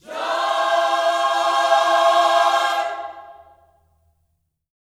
JOY CMAJ 1.wav